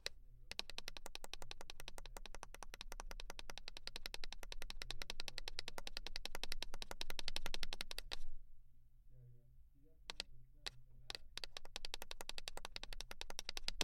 描述：Pasospequeñoscorriendo。 Grabado con grabadora zoomH4n
Tag: 喽罗 脚步声